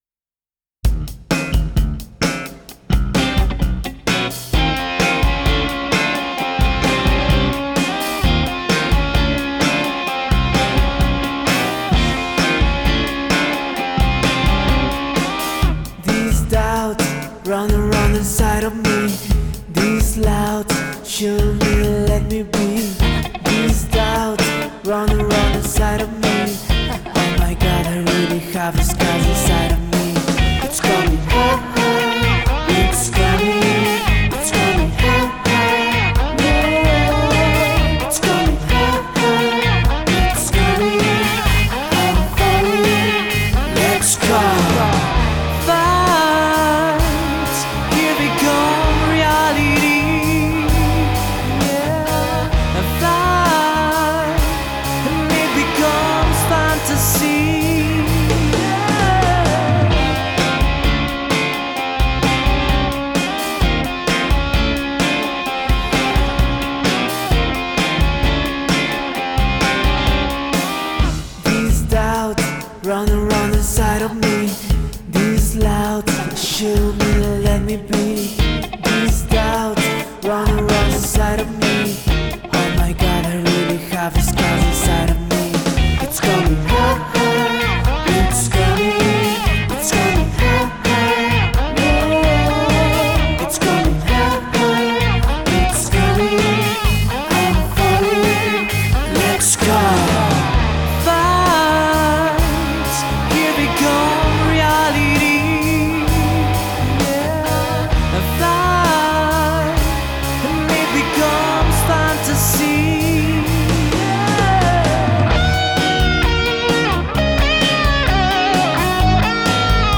Repositorio Institucional Universidad de Cuenca: Grabación y producción de dos temas inéditos en género rock fusión realizados en un “home studio”
Rock fusión
Home studio